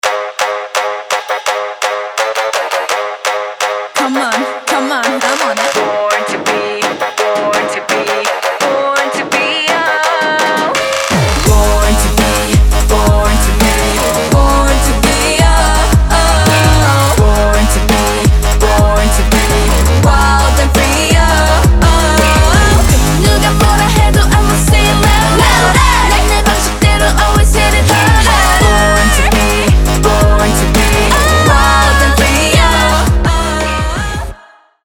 поп , рэп , trap , корейские , крутые , нарастающие , k-pop